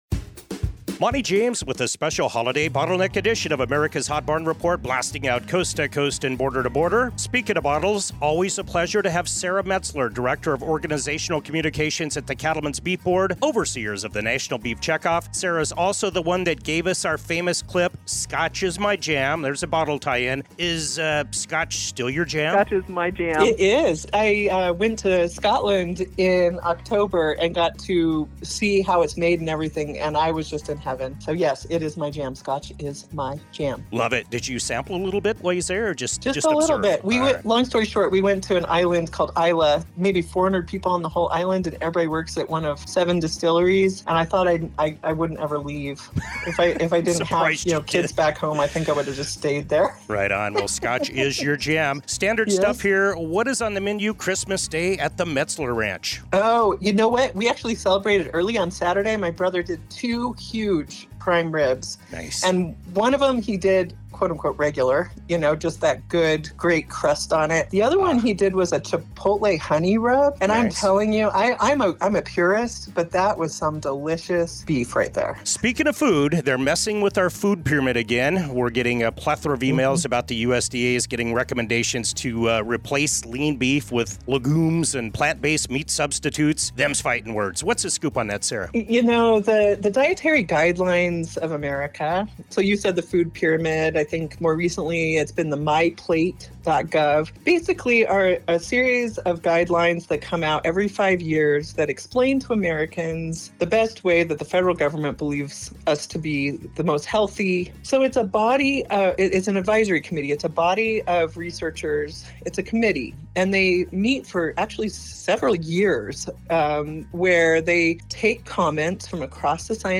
Special Interview W